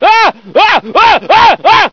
lots of screaming scientists
scream07.ogg